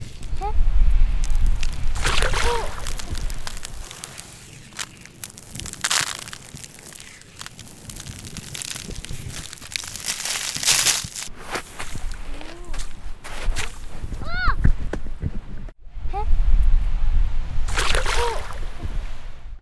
모래놀이.mp3